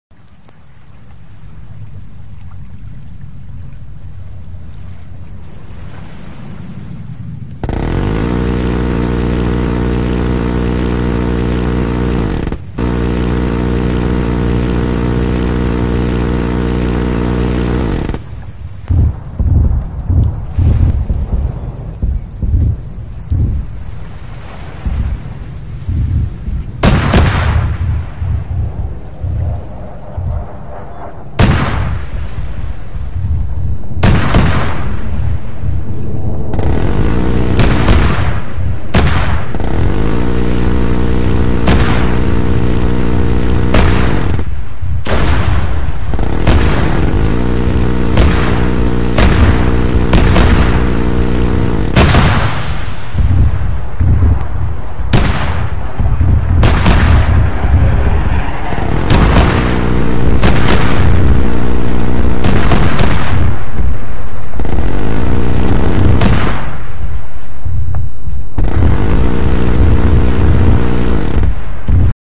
C RAM Air Defense System In Sound Effects Free Download